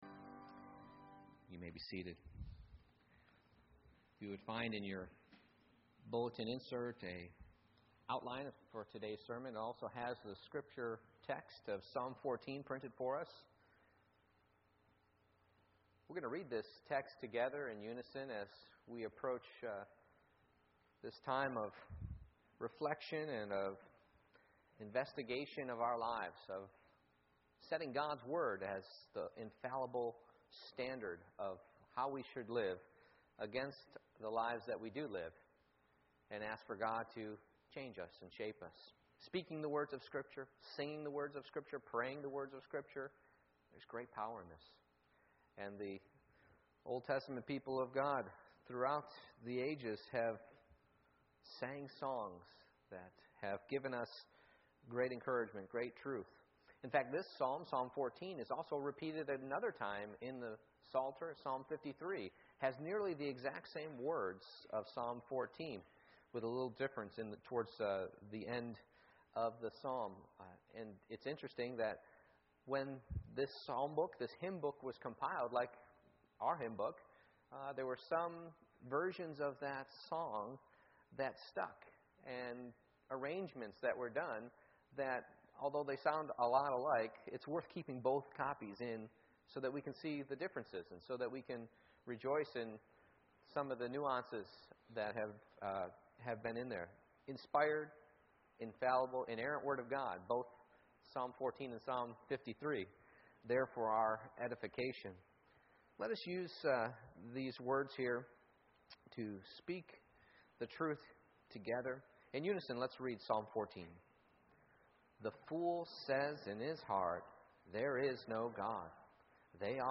Psalm 14:1-7 Service Type: Morning Worship I. Who Is the Fool?